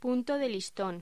Locución: Punto de listón
voz
locución
Sonidos: Voz humana